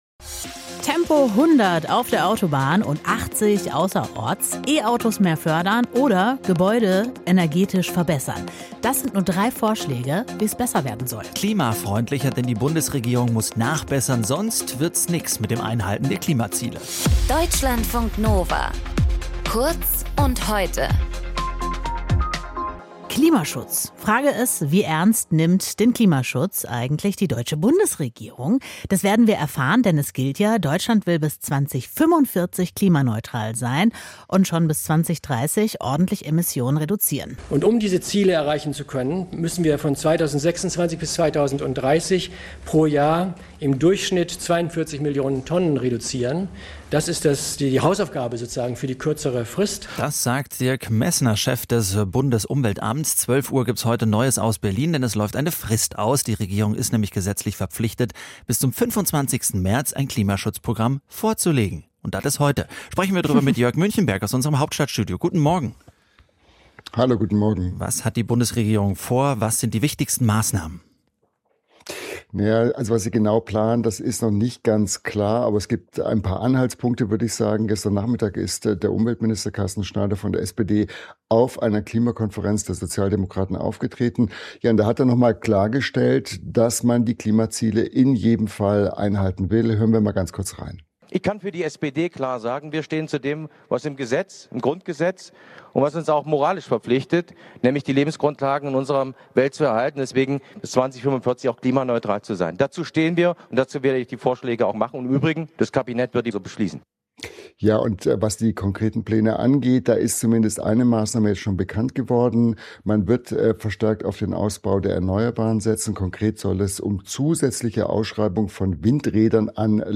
In dieser Folge mit: